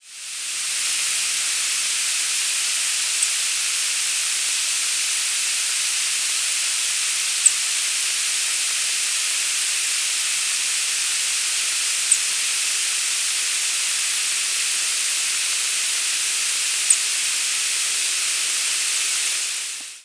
Bachman's Sparrow diurnal flight calls
Diurnal calling sequences:
Perched bird.